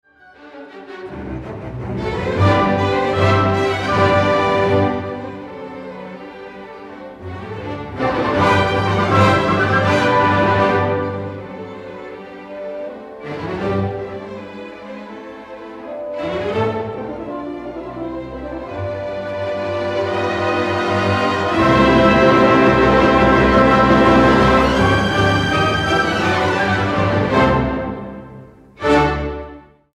Fanfary w drugiej części są powolne, masywne i ociężałe: